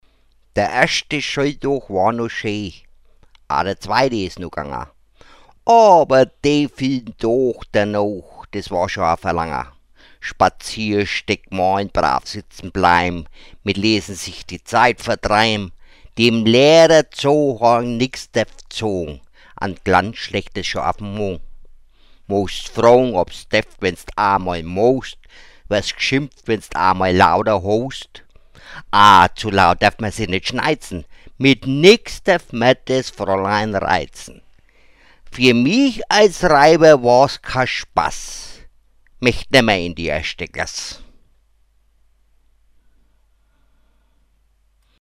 Mundart-Gedichte